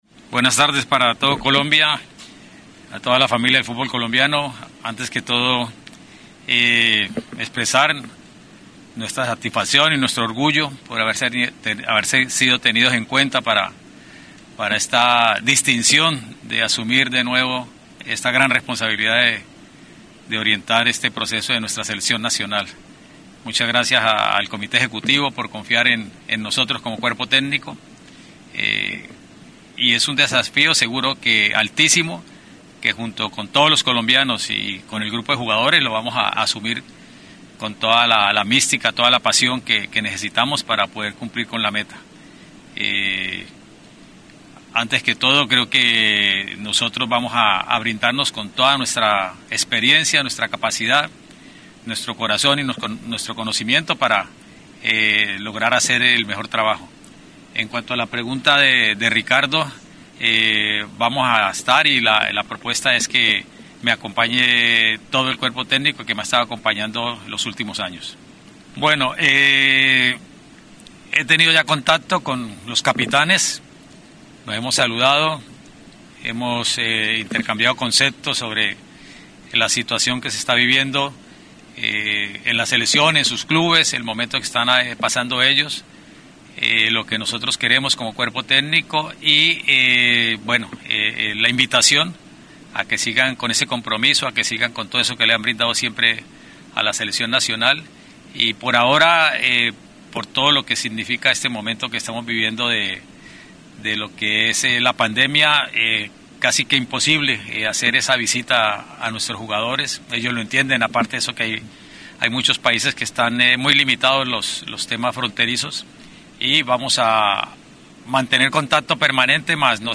RUEDA-DE-PRENSA-RUEDA.mp3